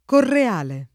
Correale [ korre # le ]